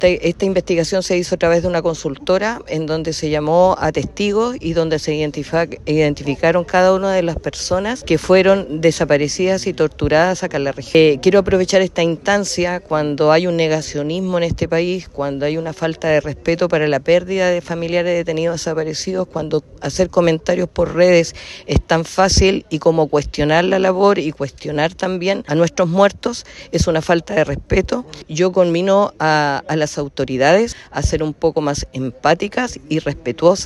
La seremi de Bienes Nacionales en Los Lagos, Pamela Lagos, detalló que el catastro regional contempla 32 sitios asociados a violaciones de estos.